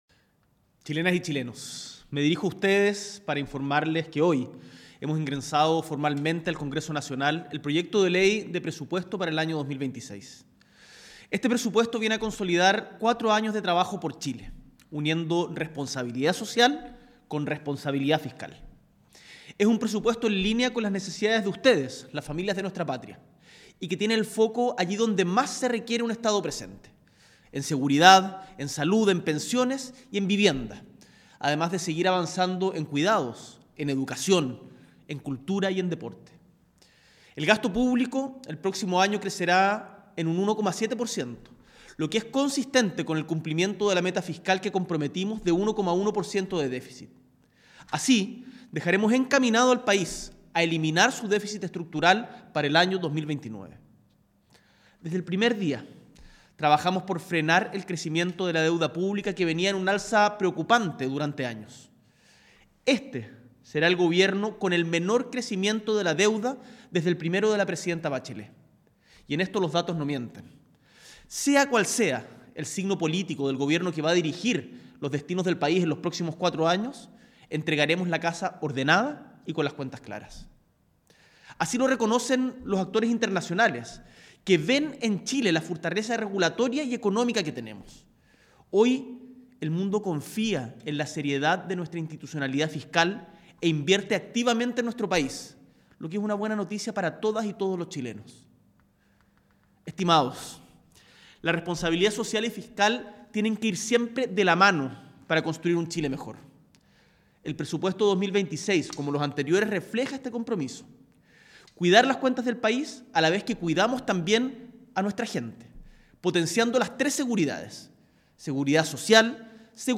A las 22.05 horas de este martes 30 de septiembre, S.E. el Presidente de la República, Gabriel Boric Font, se dirigió al país en Cadena Nacional desde el Palacio de La Moneda, para detallar los principales aspectos del Proyecto de Ley de Presupuesto de la Nación del año 2026, que ingresó al Congreso para su discusión.